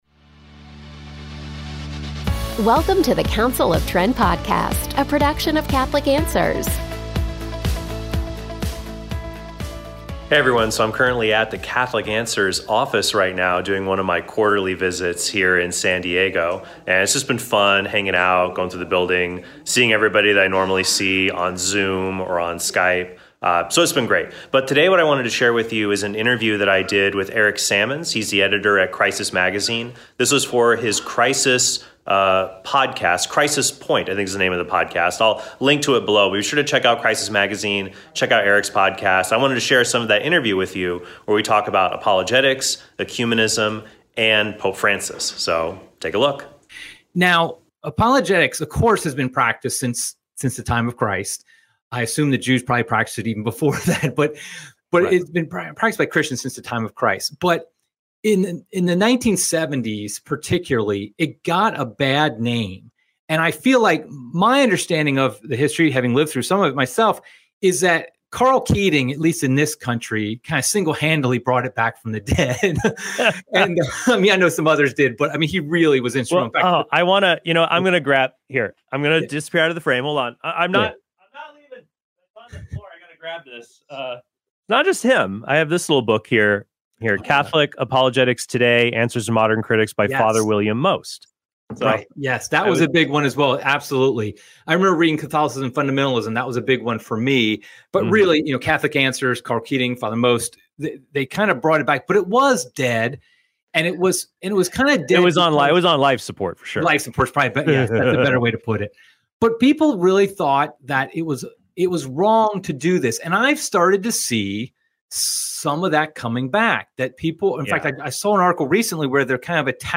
I wanted to share some of that interview with you where we talk about apologetics, ecumenism, and Pope Francis.